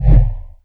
gravity_switch.wav